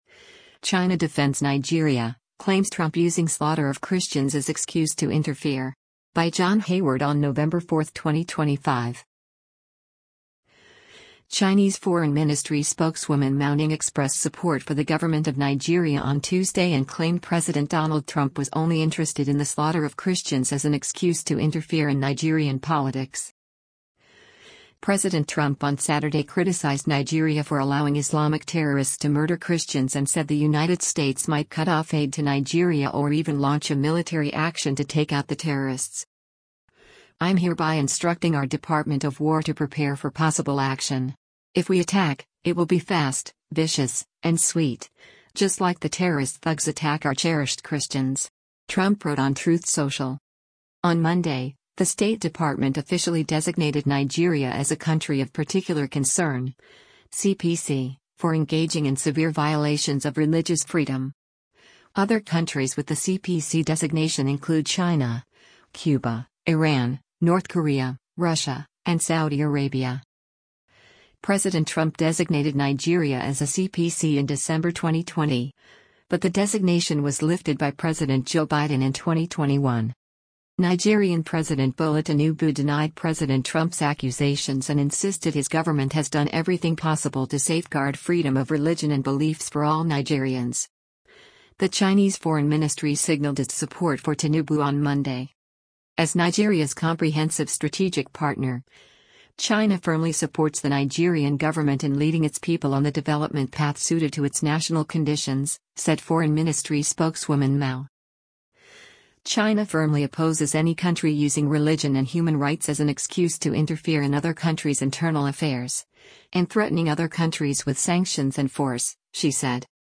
Foreign Ministry Spokesperson Mao Ning’s Regular Press Conference on November 4, 2025